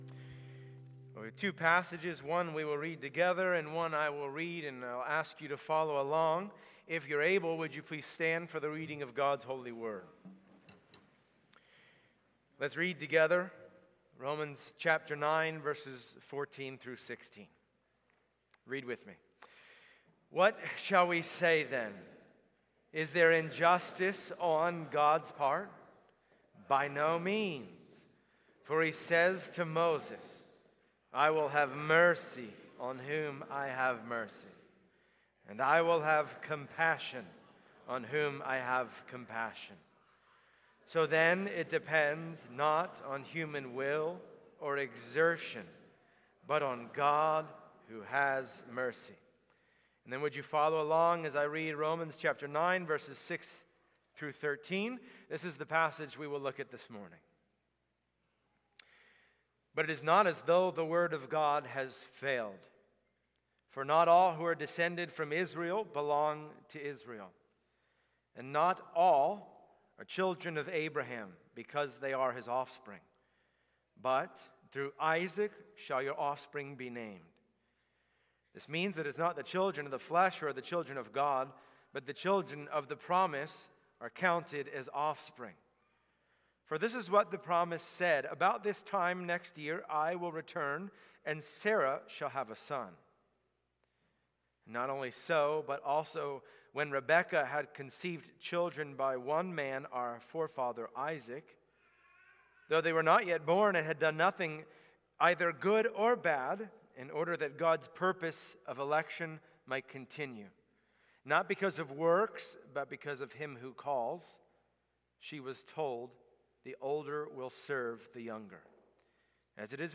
Passage: Romans 9:6-13 Service Type: Sunday Morning Download Files Bulletin « The Chapter Everyone Wants to Ignore The Just God?